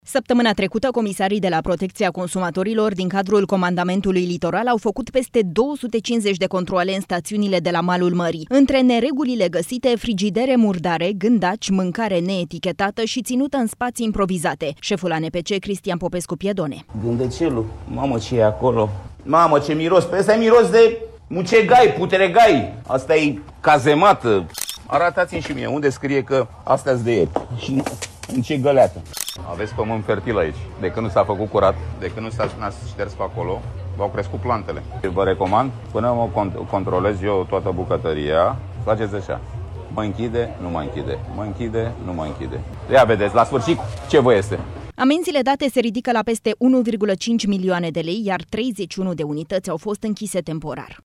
Șeful ANPC, Cristian Popescu Piedone: „Asta-i cazemată”